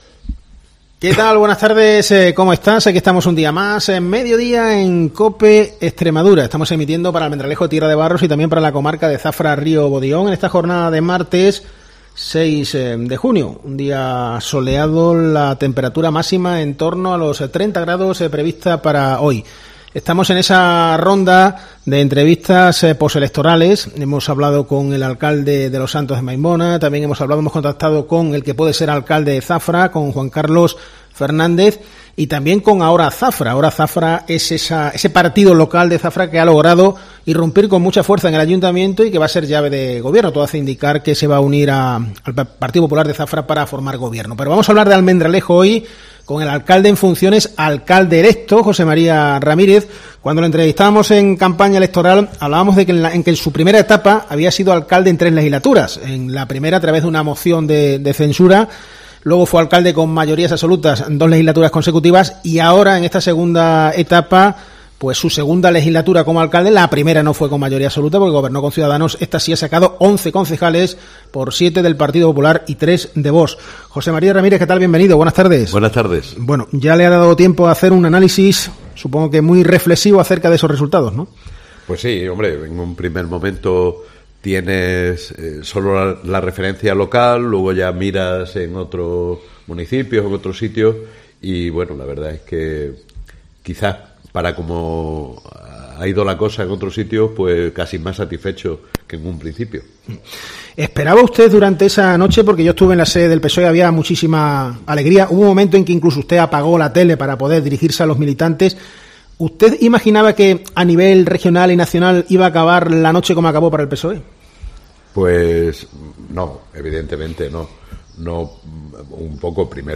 Entrevista al alcalde electo de Almendralejo, José María Ramírez